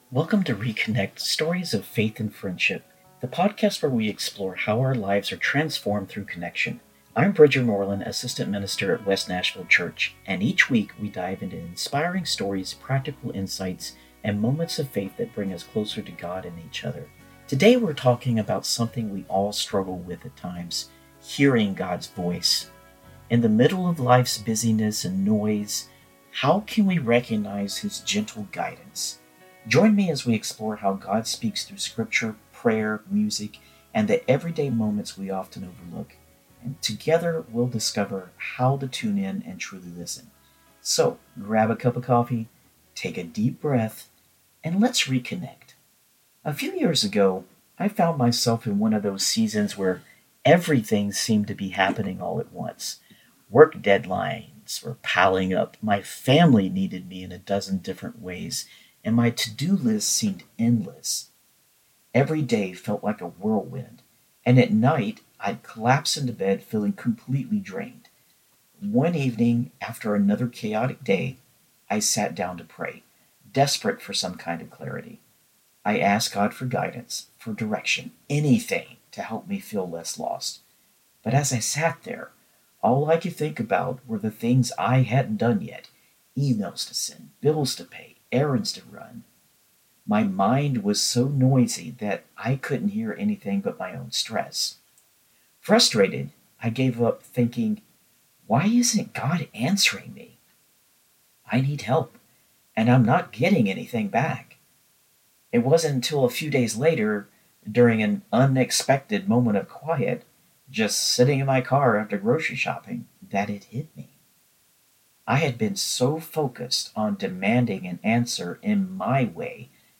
Join us for this inspiring conversation and discover how to listen for God’s gentle voice, even in life’s chaos.